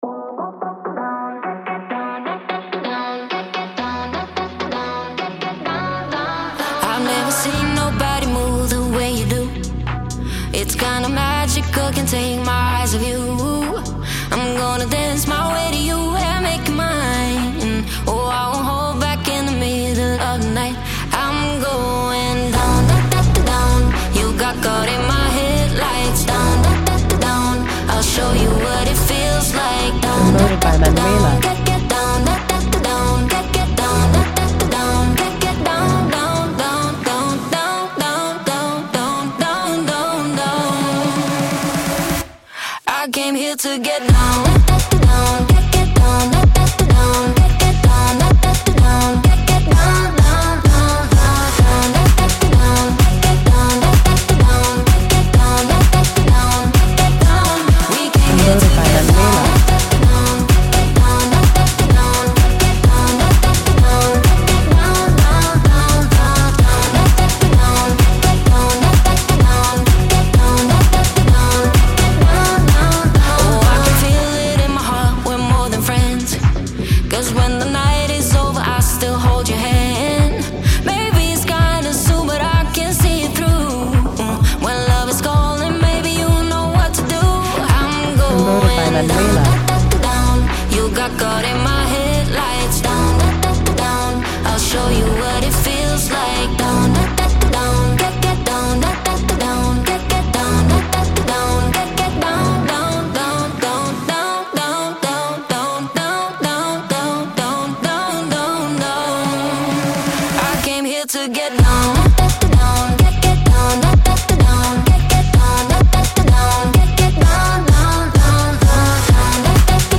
Radio Edit